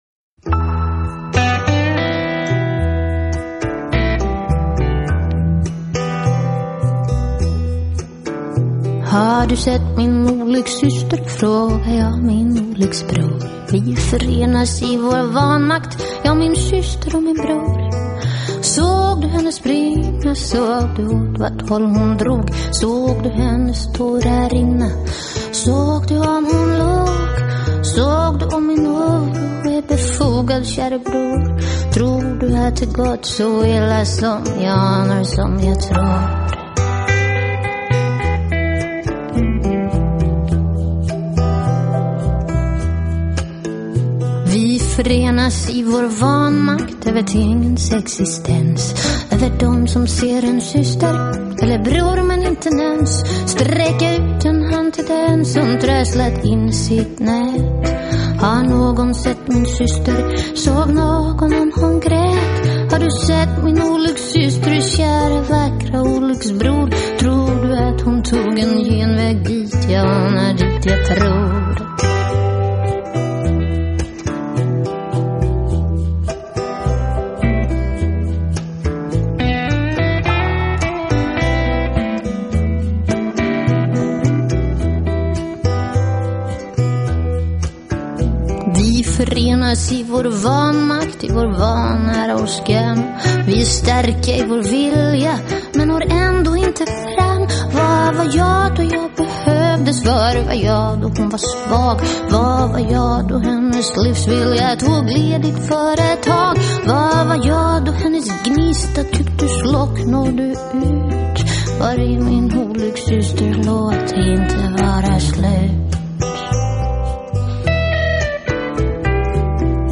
以口琴、电子风琴、乡村蓝调的乐风谱成狂野的北欧式吶喊
较为成熟的口吻，给人一种饱经世事的中年妇女的沧桑感，格外让这十一首全部由她一人
哮和独特的瑞典吉他，是一首黑色幽默的歌曲。